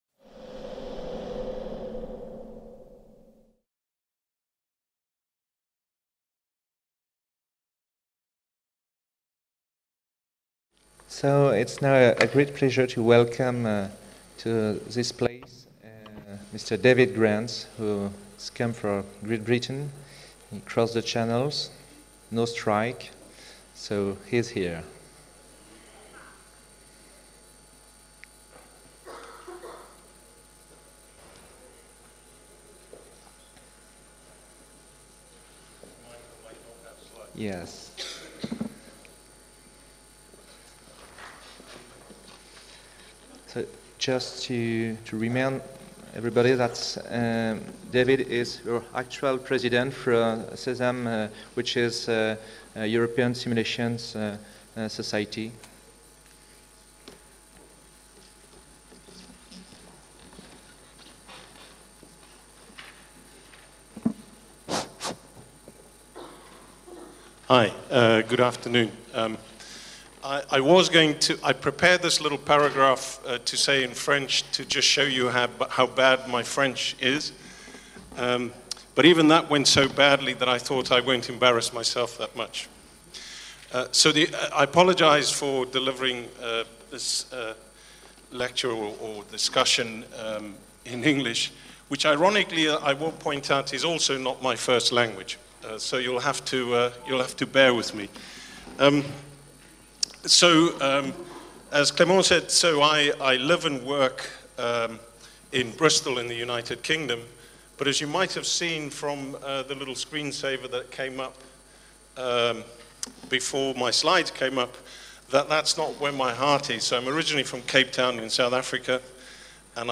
SOFRASIMS 2018 | 10 - Facteur humain / CRM en simulation (version anglaise) | Canal U